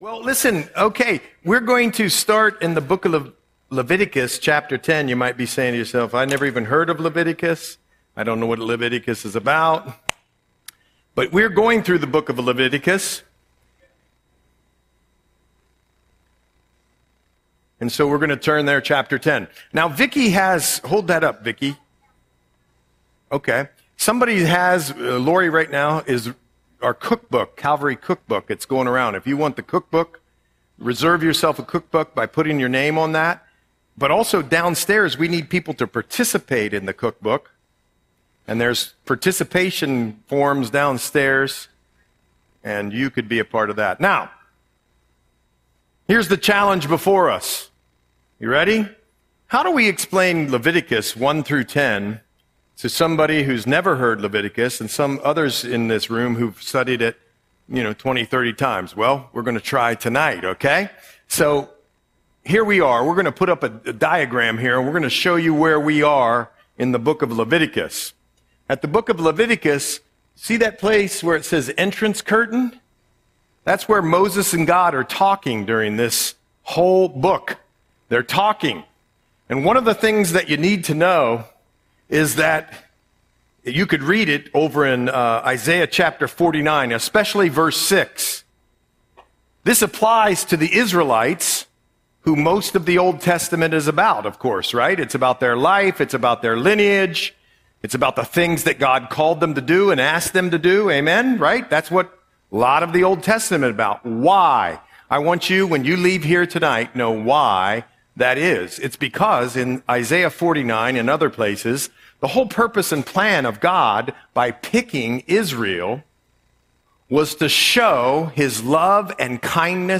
Audio Sermon - October 8, 2025